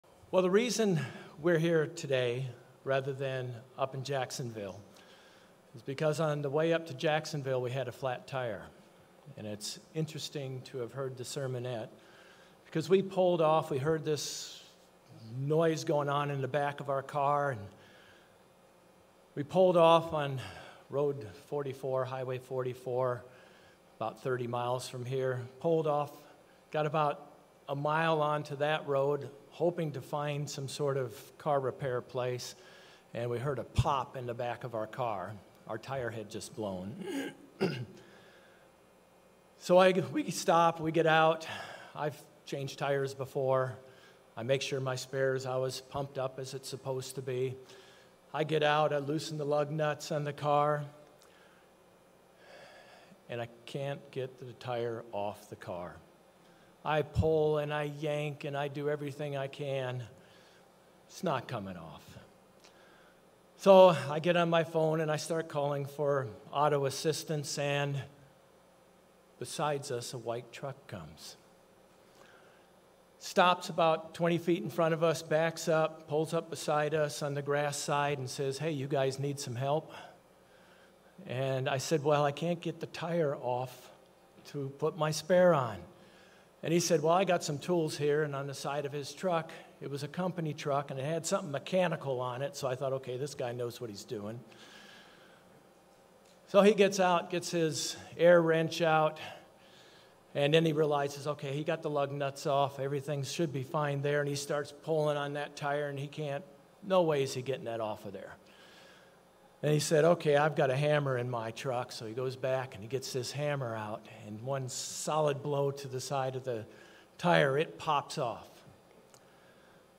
Sermons
Given in Orlando, FL Jacksonville, FL